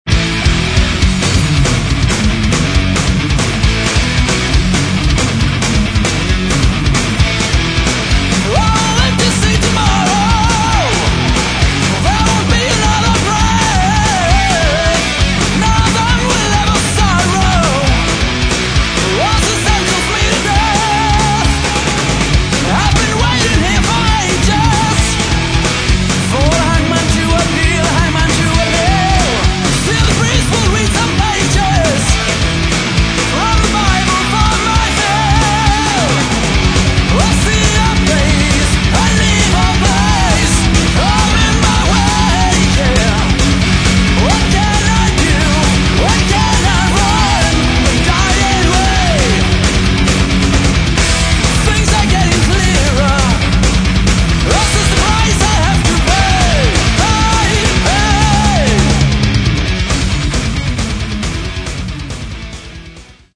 Metal
Древняя битва между Добром и Злом в стиле Speed Power Metal!